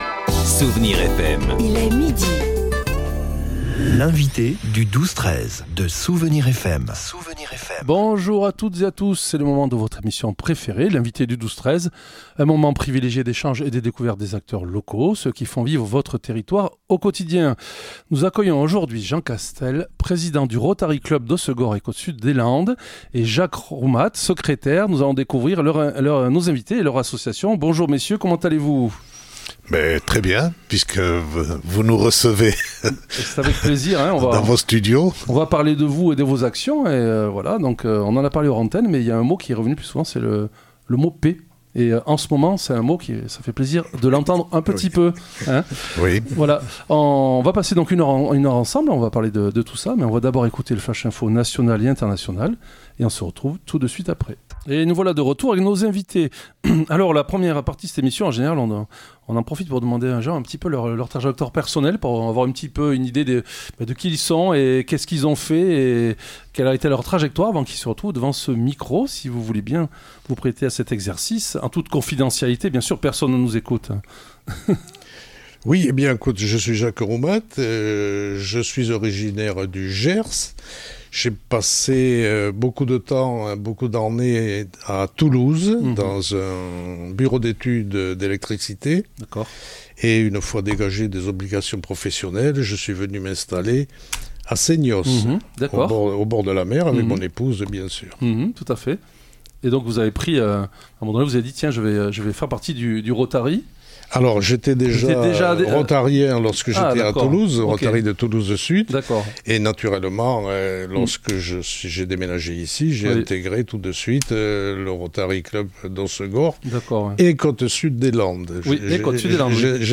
Et en écoutant nos invités, vous comprendrez que ce ne sont pas de simples mots mais que par l'intermédiaire de leur club local et grâce à la force d'une très importante association mondiale, ils œuvrent pour la paix, le partage, le soutient et la solidarité.